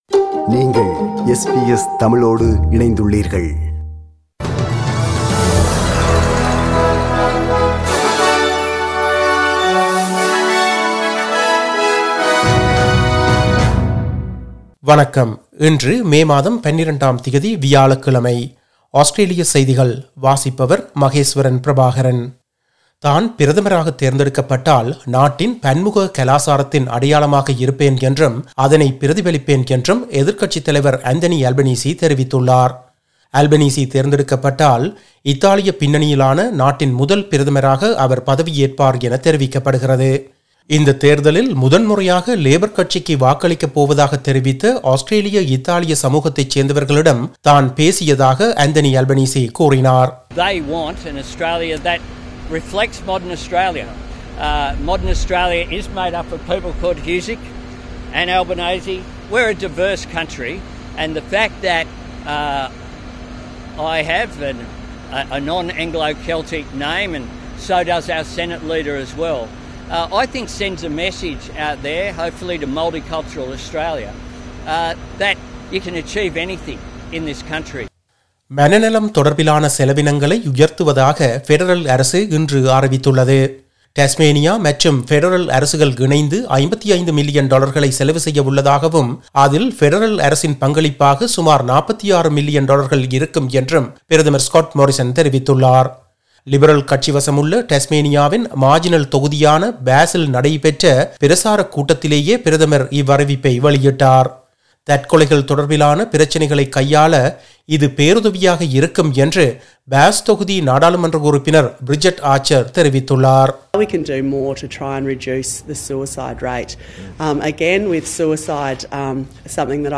Australian news bulletin for Thursday 12 May 2022.